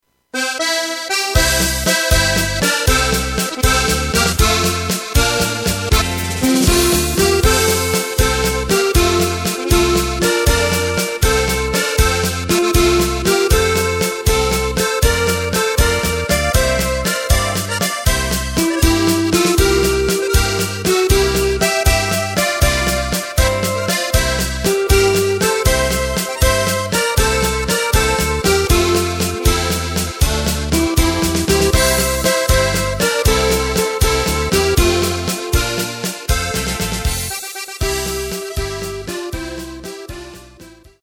Takt:          3/4
Tempo:         237.00
Tonart:            F
Flotter Walzer aus dem Jahr 1986!